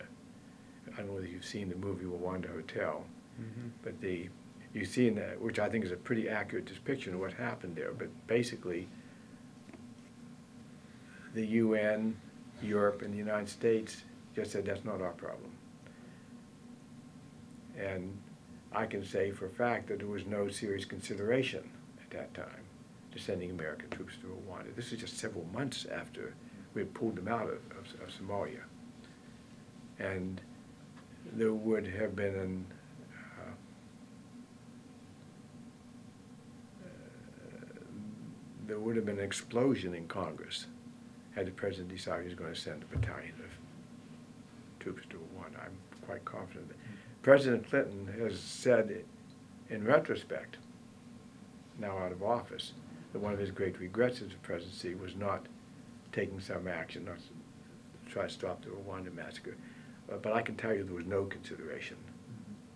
Secretary of Defense William Perry describes how the Clinton administration had little appetite for military intervention when the Rwandan Civil War turned genocidal in April. Date: February 21, 2006 Participants William Perry Associated Resources William Perry Oral History The Bill Clinton Presidential History Project Audio File Transcript